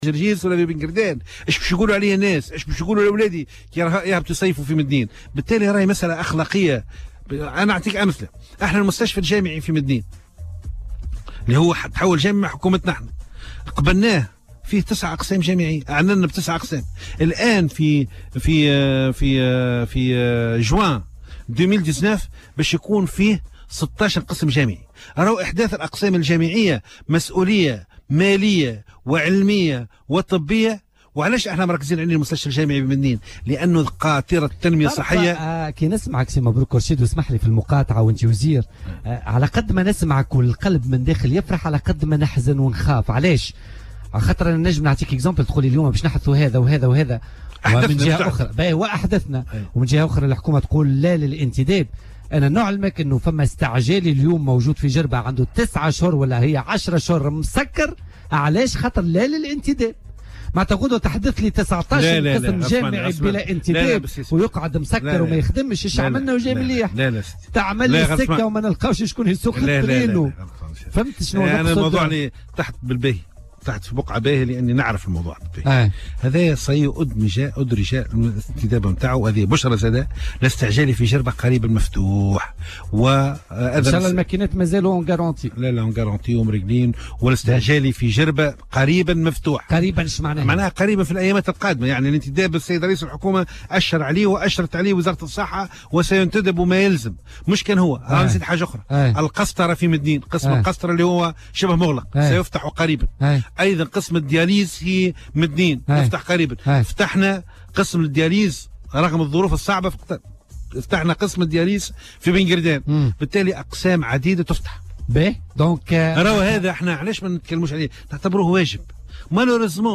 وأوضح ضيف "بوليتكا" على "الجوهرة اف أم" ان مستشفى مدنين الجامعي سيتوسع إلى 16 قسما بحلول جوان 2019 مقابل 9 أقسام حاليا مشيرا في هذا الصدد إلى انه سيتم فتح قسم للقسطرة وآخر لتصفية الدم قريبا.